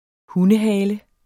Udtale [ ˈhunəˌ- ]